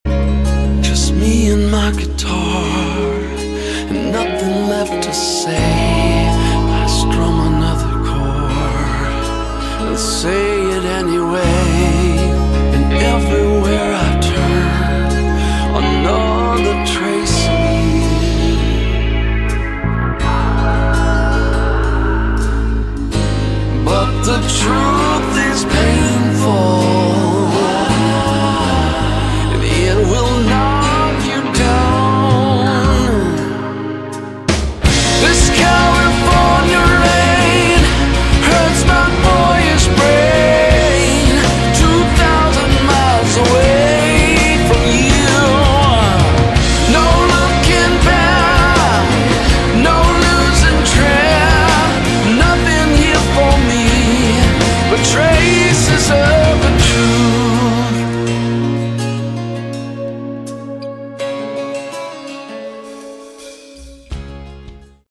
Category: Hard Rock
vocals, guitar
drums